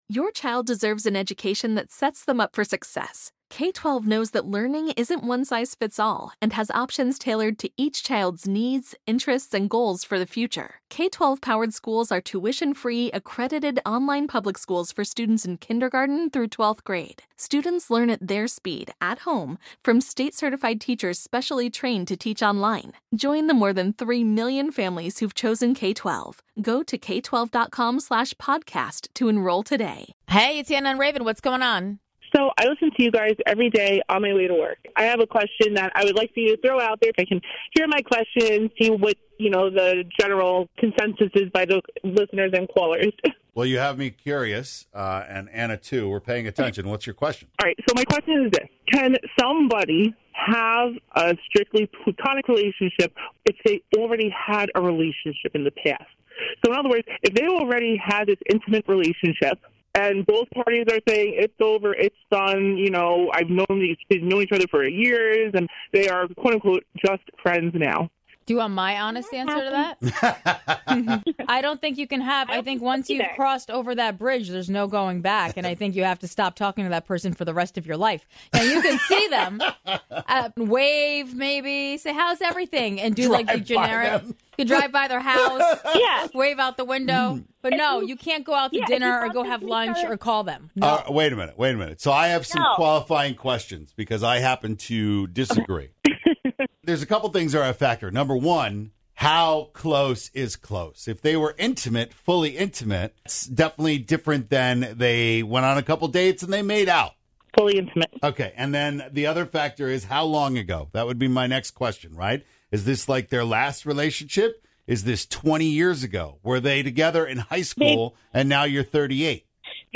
Can you have a platonic relationship with someone you were intimate with in the past? A caller shared her story to get your opinion...